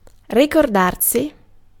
Vaihtoehtoiset kirjoitusmuodot (vanhentunut) remembre Synonyymit recall reminisce recollect bethink look back Ääntäminen US : IPA : [ɹi.ˈmem.bəɹ] UK GenAm: IPA : /ɹɪˈmɛmbɚ/ IPA : /ˈmɛmbɚ/ RP : IPA : /ɹɪˈmɛmbə/